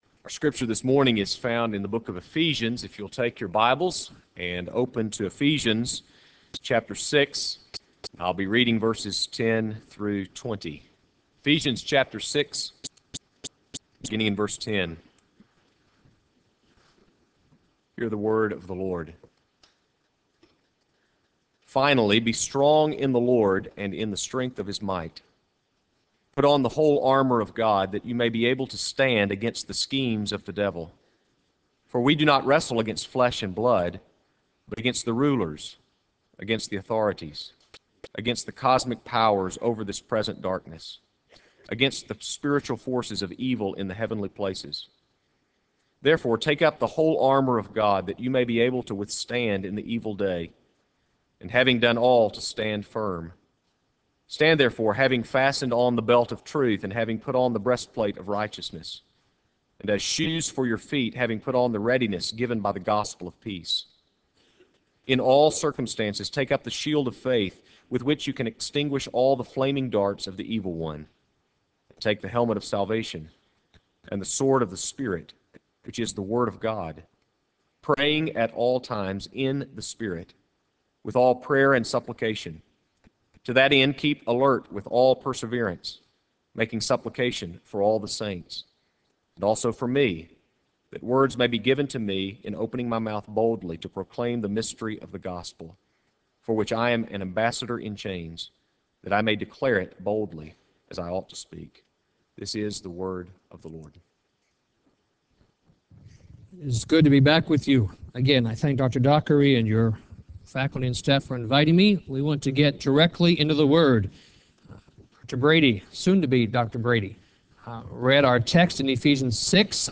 Faith in Practice Conference